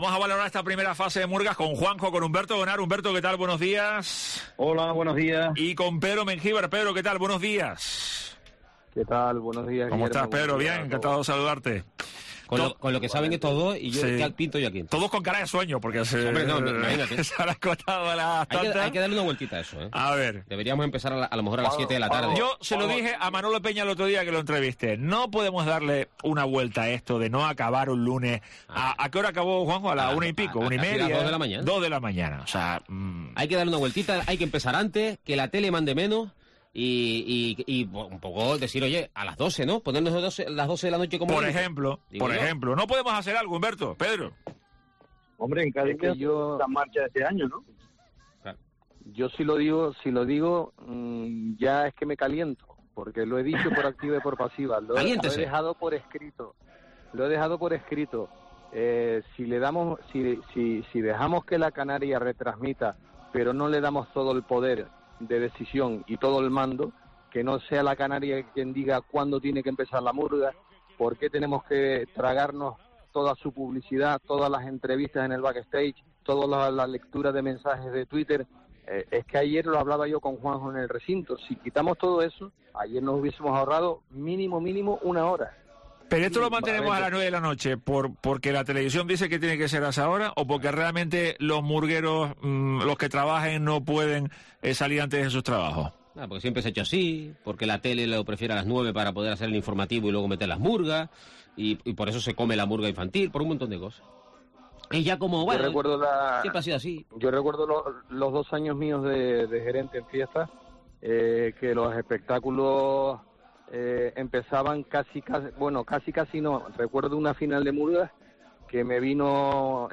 Carnaval en COPE Tenerife: tertulia de la primera fase del concurso de murgas